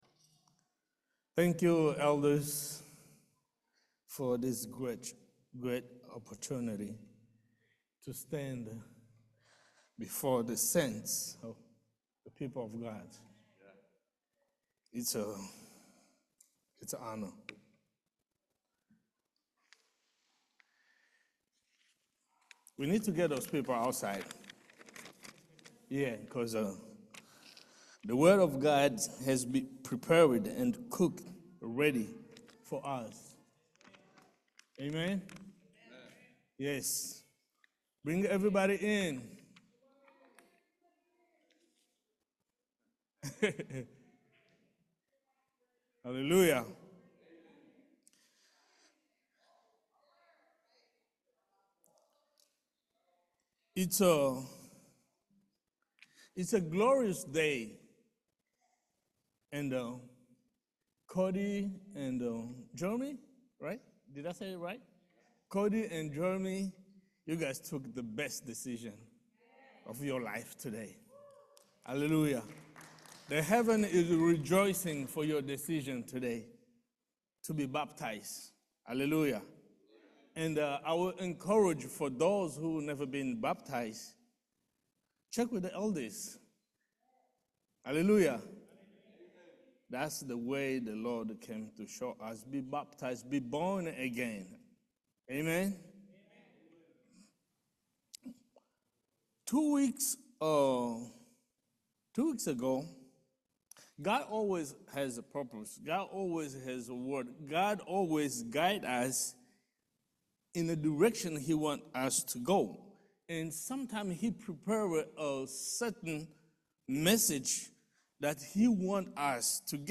John 14:6 Service Type: Main Service We need to know the impact of the word of God.